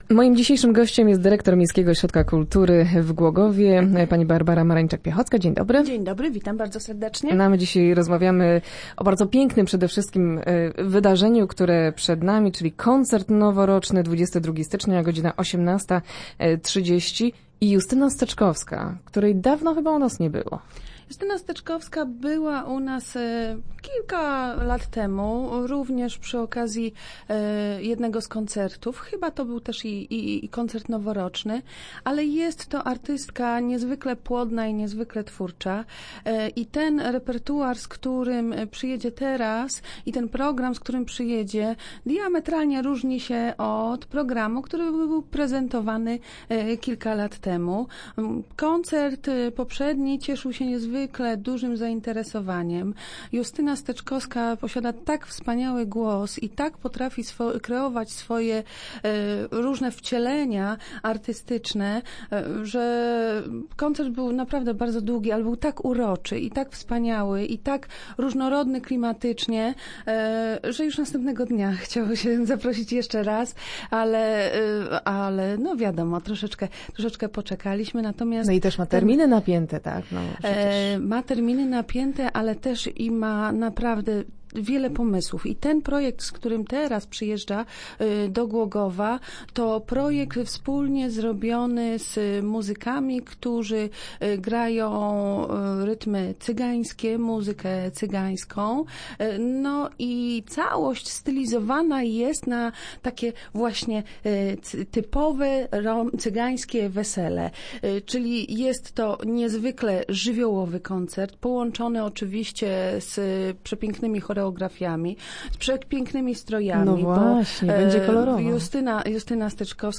Start arrow Rozmowy Elki arrow Koncert Noworoczny